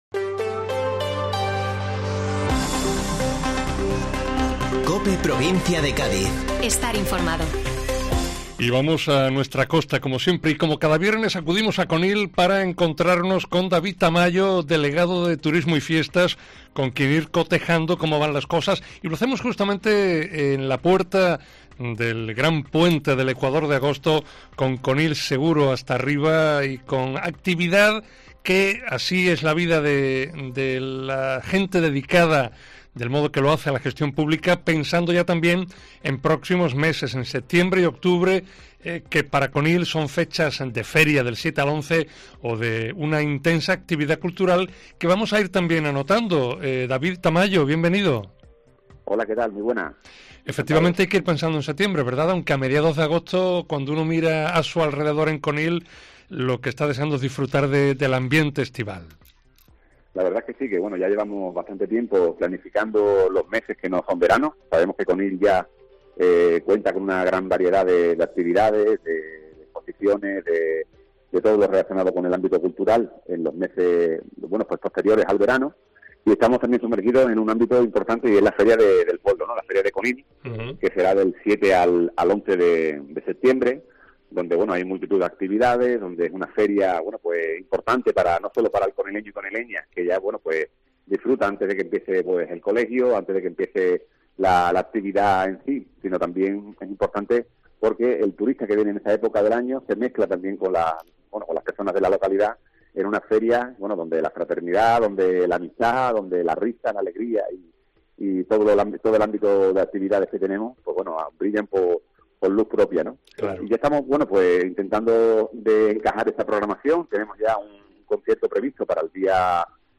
David Tamayo, delegado de Turismo y Fiestas, prepara ya con su equipo esta fiesta que, en honor de Nuestra Señora de las Virtudes, tendrá lugar del 7 al 11 de Septiembre